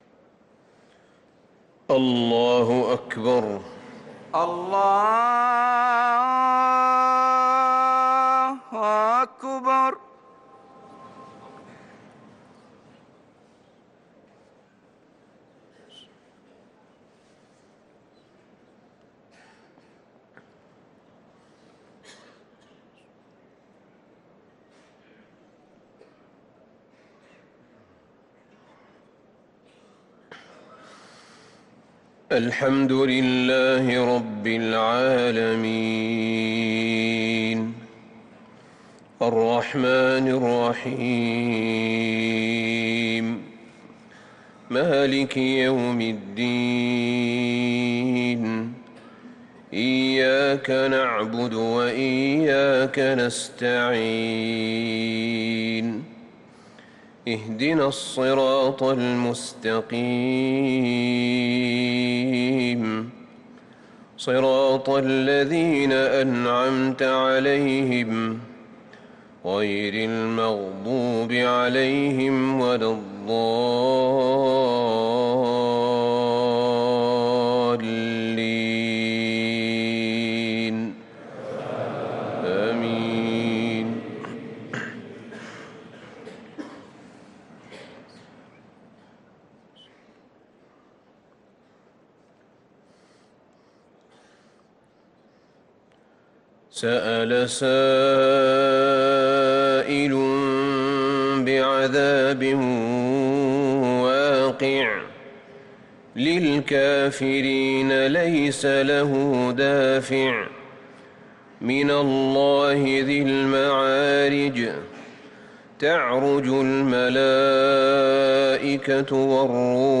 صلاة الفجر للقارئ أحمد بن طالب حميد 15 جمادي الأول 1445 هـ
تِلَاوَات الْحَرَمَيْن .